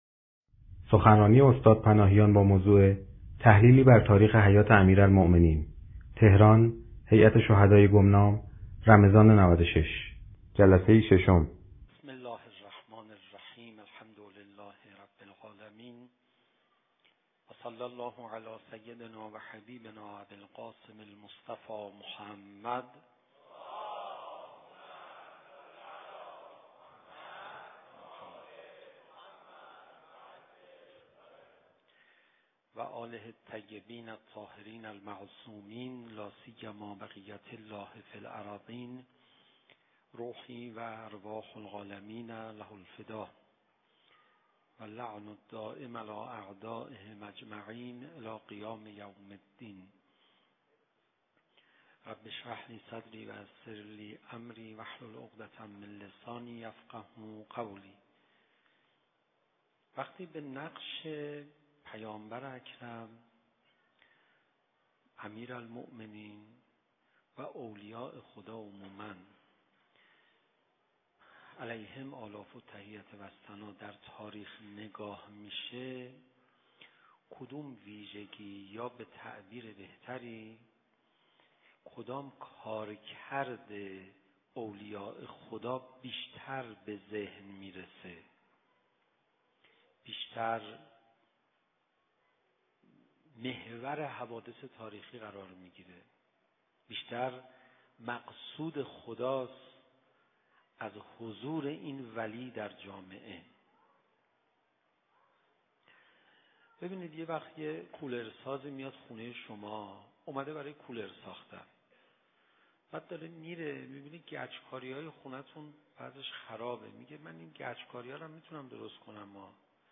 شب ششم ماه رمضان_هیئت شهدای گمنام _تحلیلی بر تاریخ حیات امیرالمؤمنین(علیه السلام)
سخنرانی